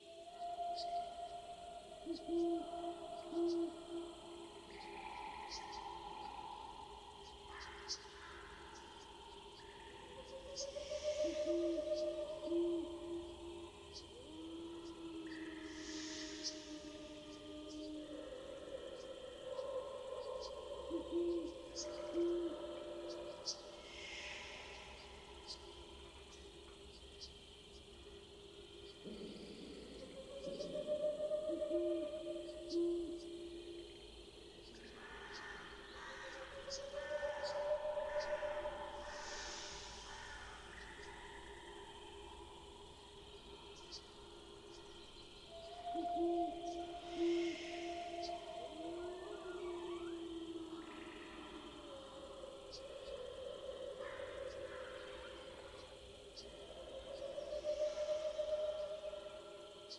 AMB_veles_forest_night.mp3